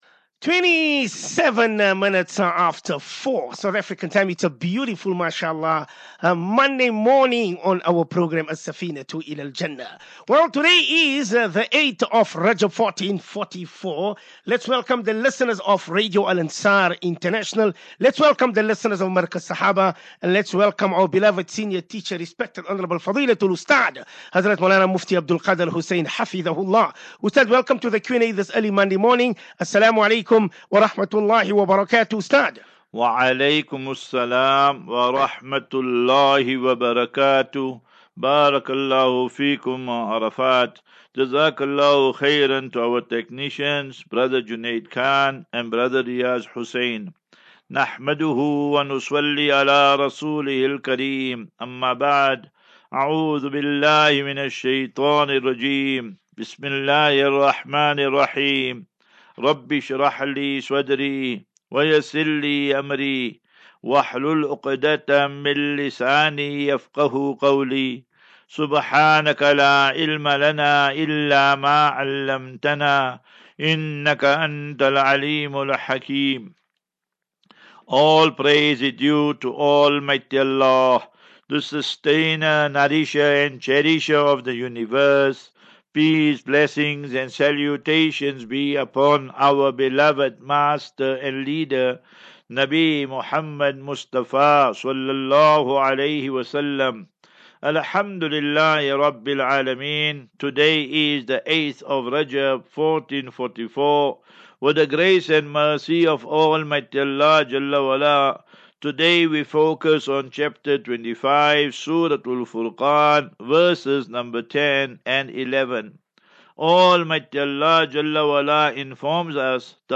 View Promo Continue Install As Safinatu Ilal Jannah Naseeha and Q and A 30 Jan 30 Jan 23- Assafinatu-Illal Jannah 39 MIN Download